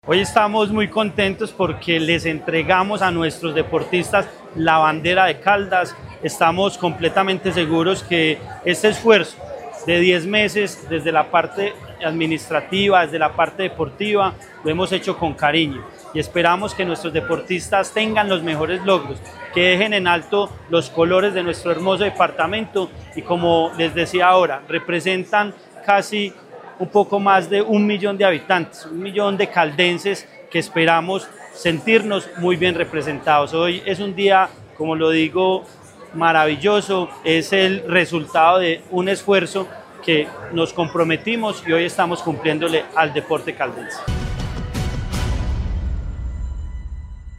Andrés Duque Osorio, secretario de Deporte de Caldas.